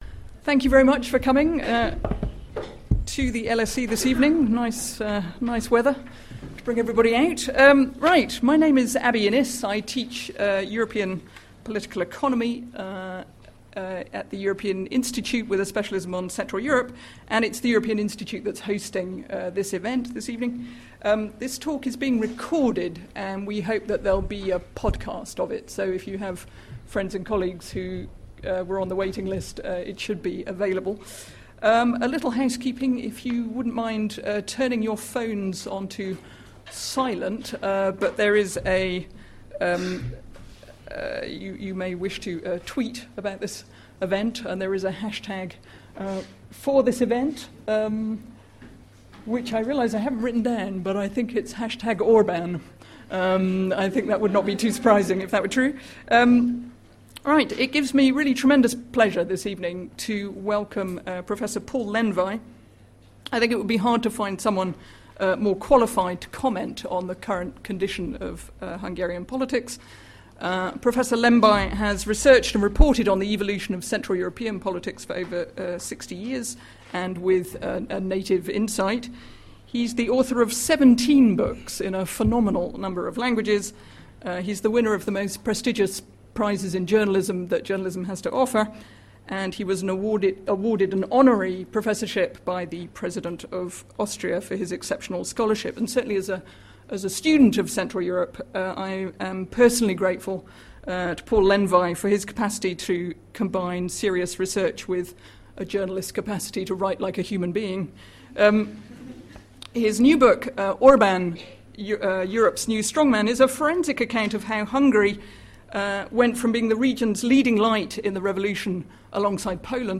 Download the podcast for the lecture by Paul Lendvai on Orban's Hungary.
Speaker: Paul Lendvai, Hungarian-born Austrian journalist who worked as a correspondent for the Financial Times for more than two decades
Recorded on 18 October 2017 in the Alumni Theatre.